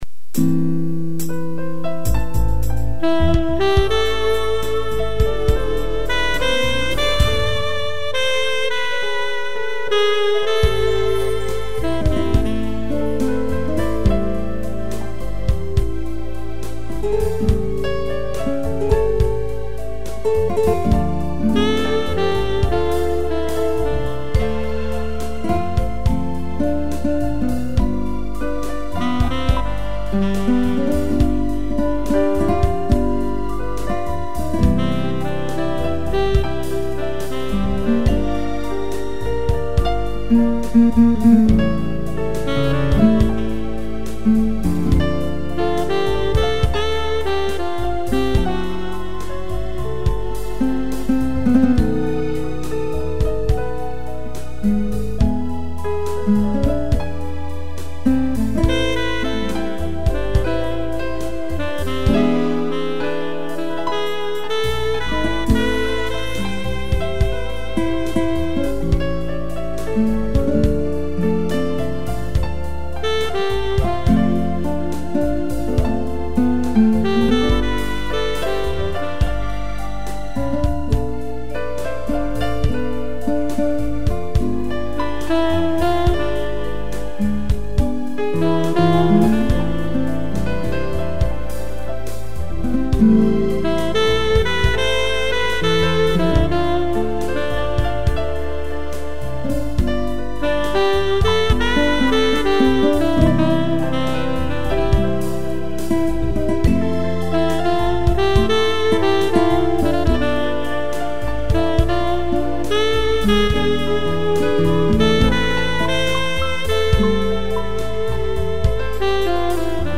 piano e sax
instrumental